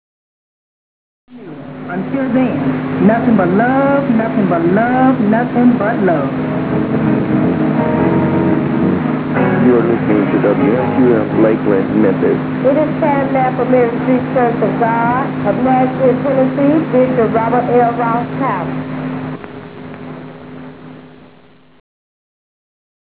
Auroral activity plus 50,000 watts just at power switch, note signal vanish!